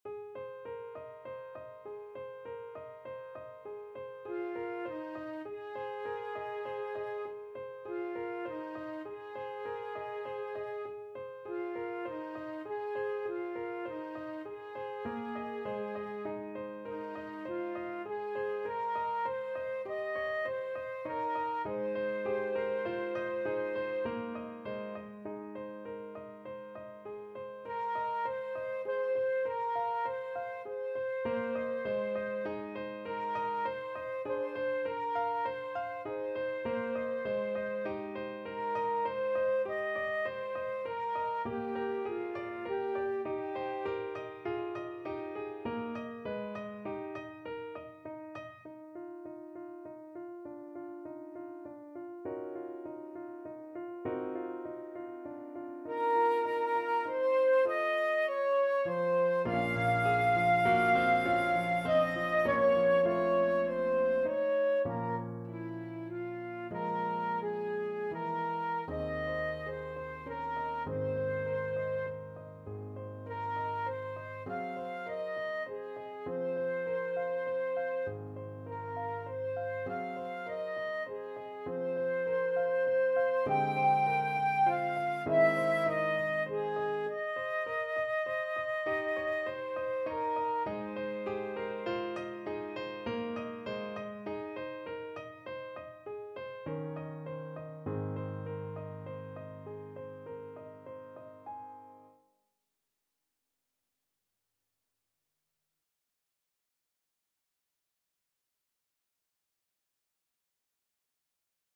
Flute version
9/4 (View more 9/4 Music)
~ = 120 Allegretto
Classical (View more Classical Flute Music)